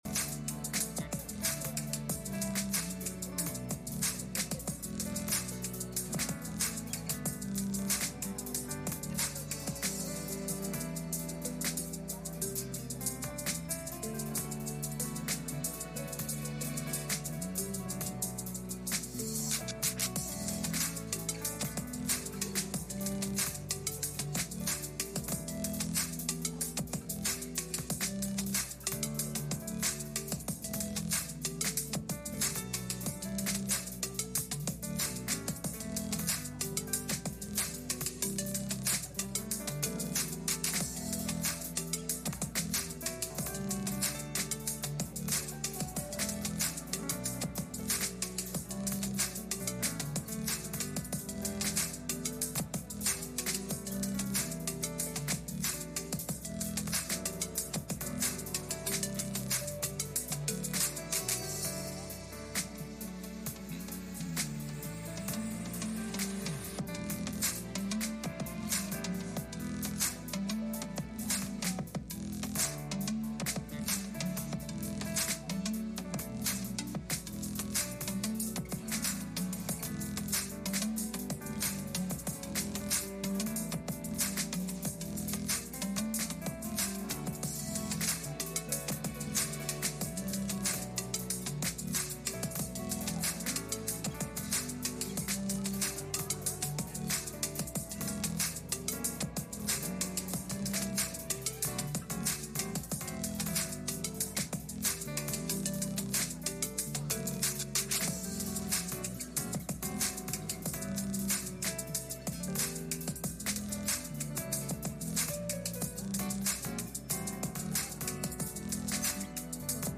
Esther 4:14 Service Type: Midweek Meeting « Crosses And Losses